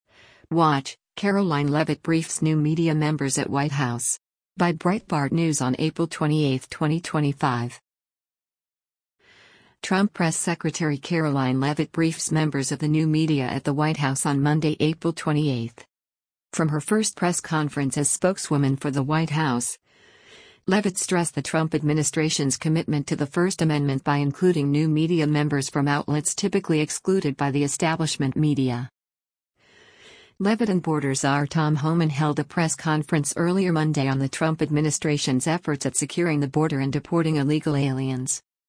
Trump Press Secretary Karoline Leavitt briefs members of the “new media” at the White House on Monday, April 28.